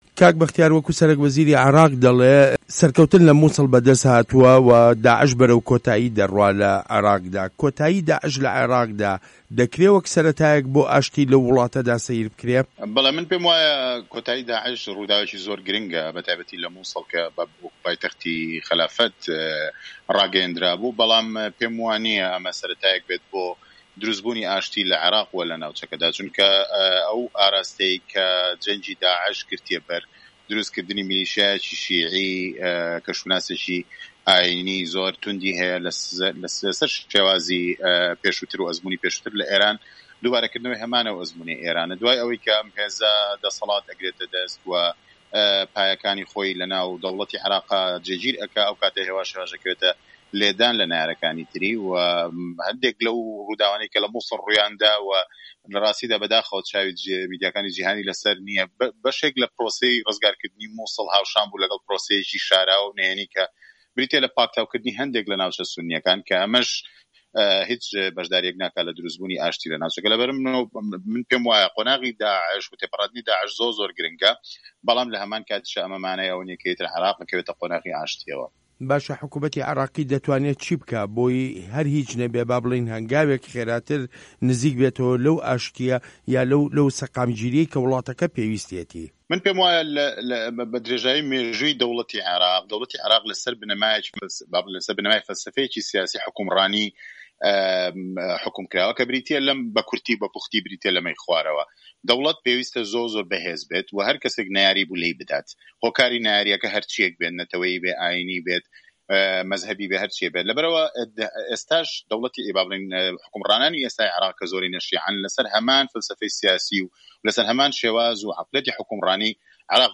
زانیاری زیاتر لە دەقی وتووێژەکەدایە: